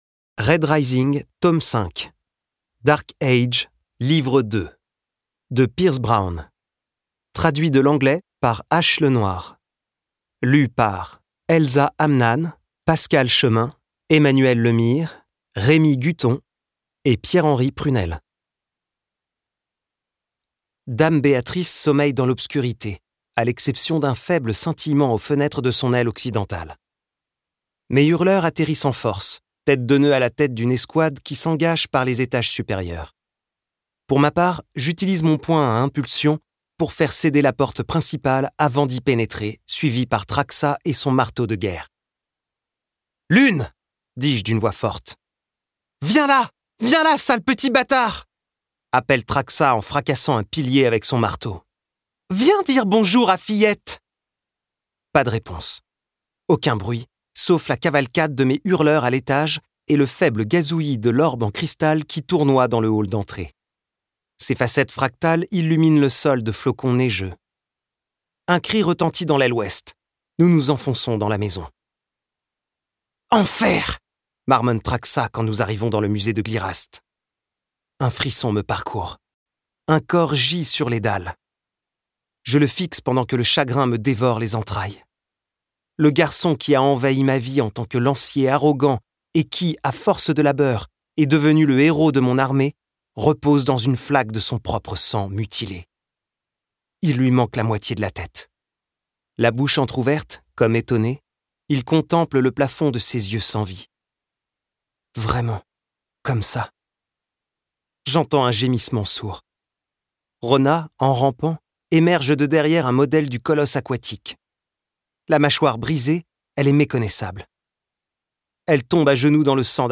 Plongez dans la suite et fin des aventures de Darrow grâce à une narration polyphonique pleine d’énergie